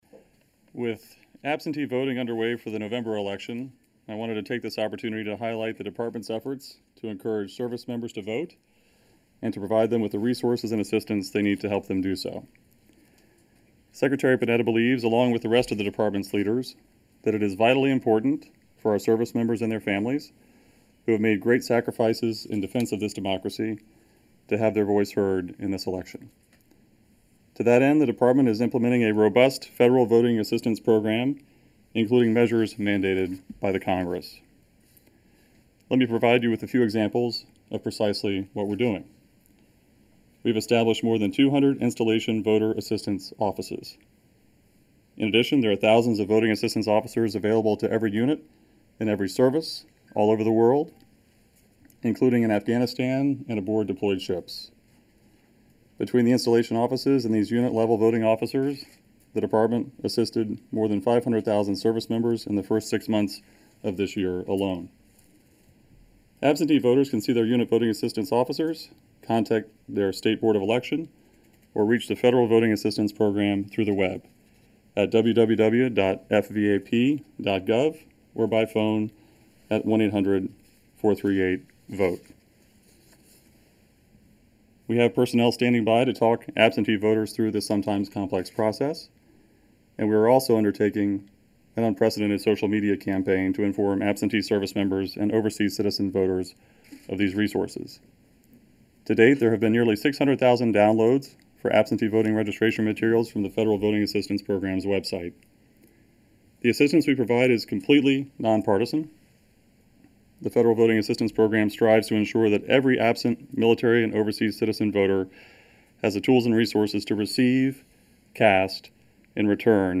George Little, acting assistant secretary of Defense for Public Affairs briefs the Pentagon press corps.